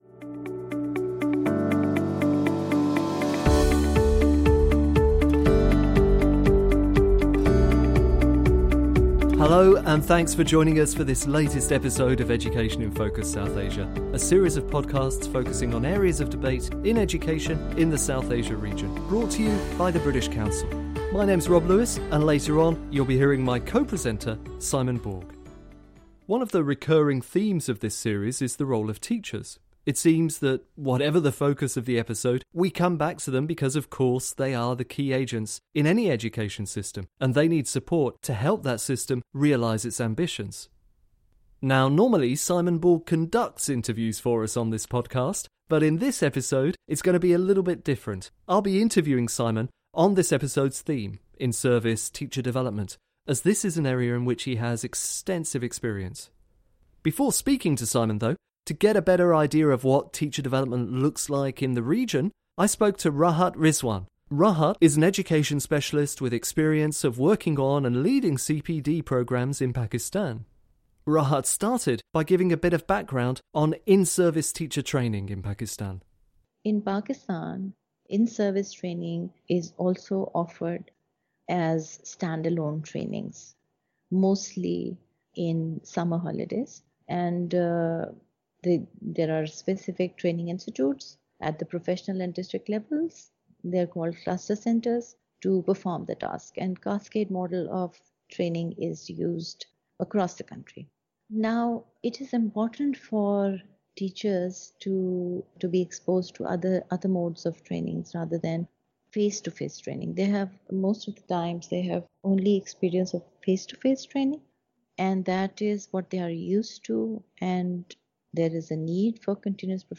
Listen to this engaging discussion about in-service teacher development.